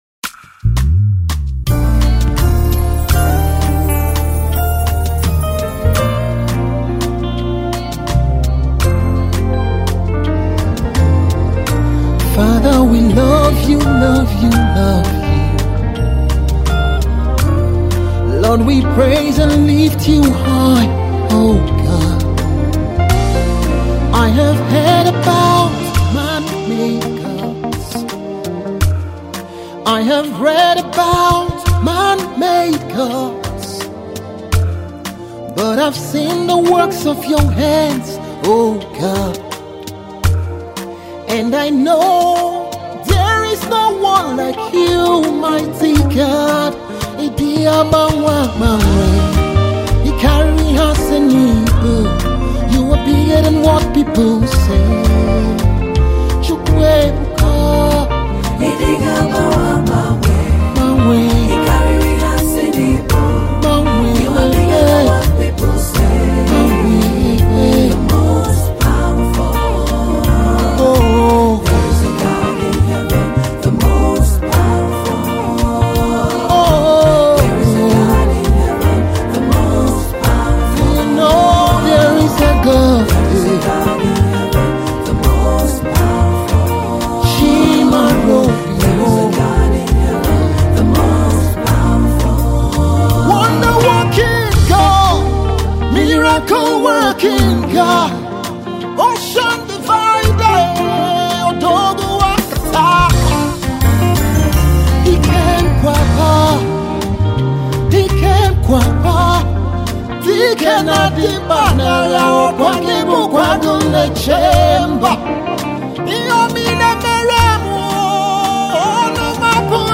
Gospel music
The song has a compelling melody that draws one in.